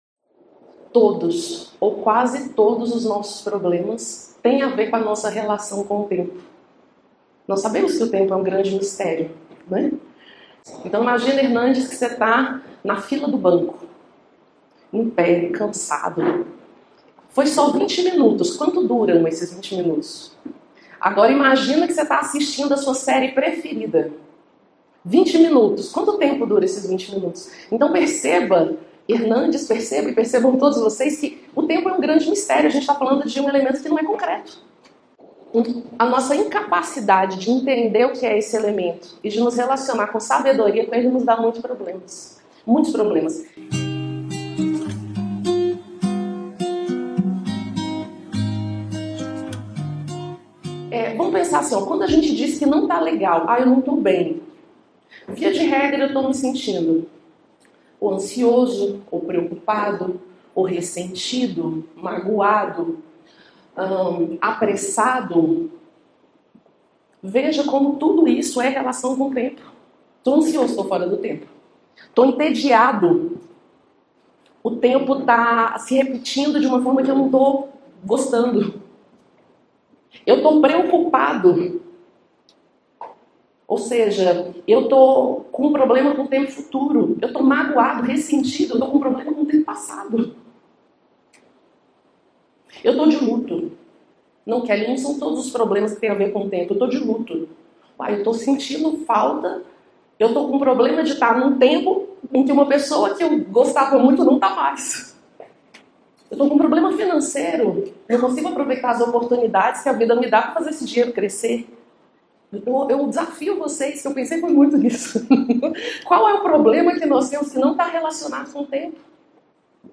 Palestras Filosóficas Nova Acrópole Podcast - #709 - COMO LIDO COM O TEMPO?